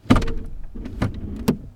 GearShifting5.WAV